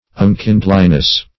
Unkindliness \Un*kind"li*ness\, n.